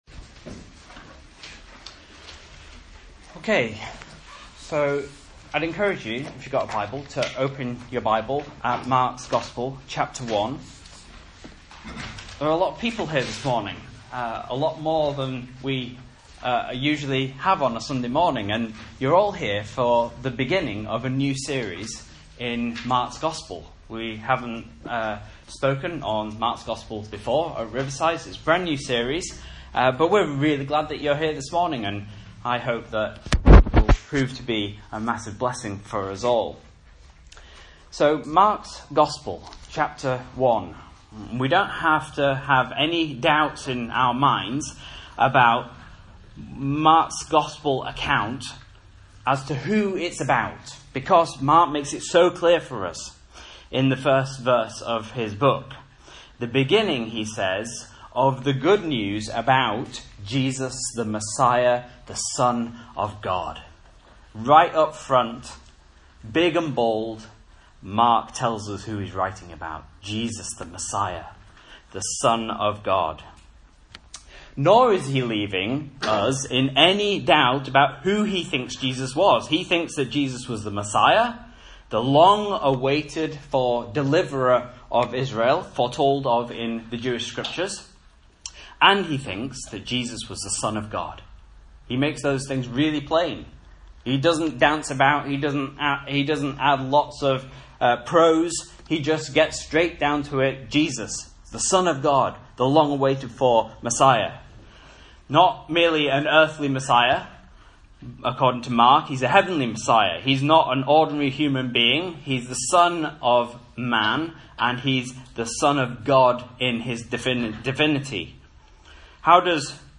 Message Scripture: Mark 1:1-11